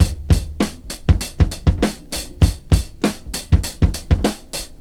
• 100 Bpm Breakbeat Sample D Key.wav
Free drum groove - kick tuned to the D note. Loudest frequency: 1037Hz
100-bpm-breakbeat-sample-d-key-R4d.wav